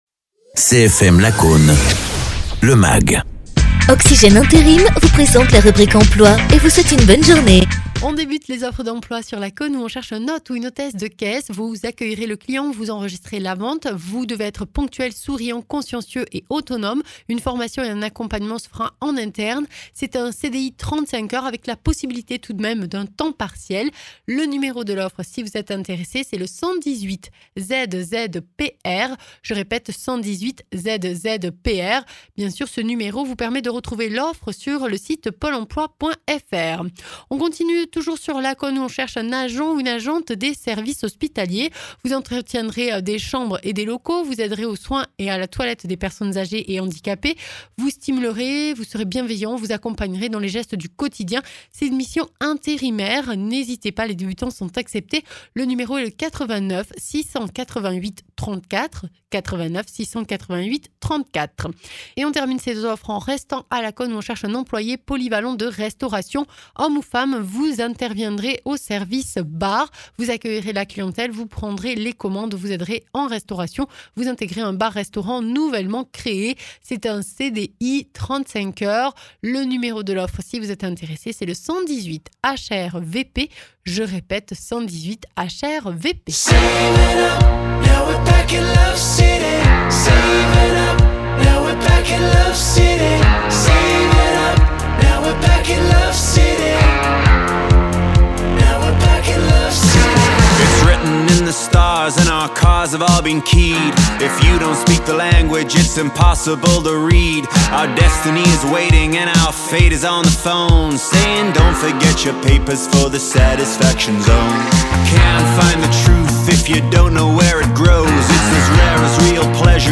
Invité(s) : Muriel Roques-Etienne, députée du Tarn; Antoine Proenca, adjoint au tourisme de la Mairie de Murat-sur-Vèbre (Tarn)